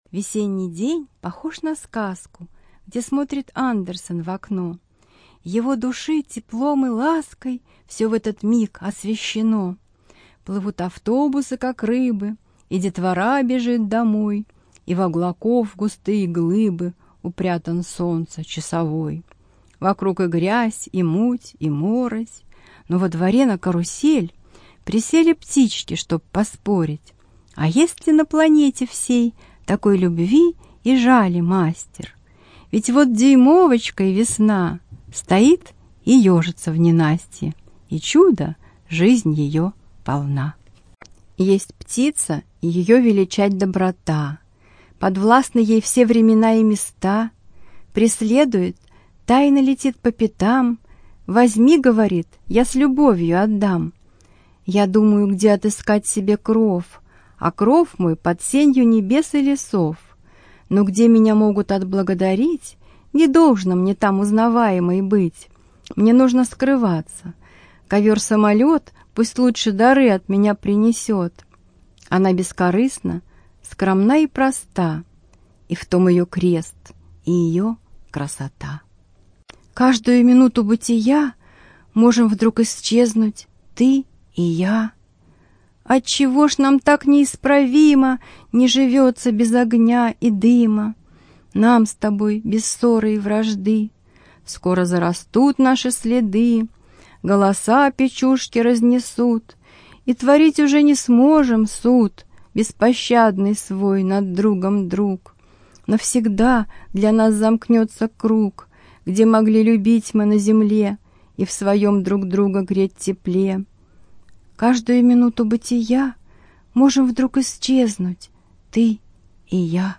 ЧитаетАвтор
Студия звукозаписиТюменская областная библиотека для слепых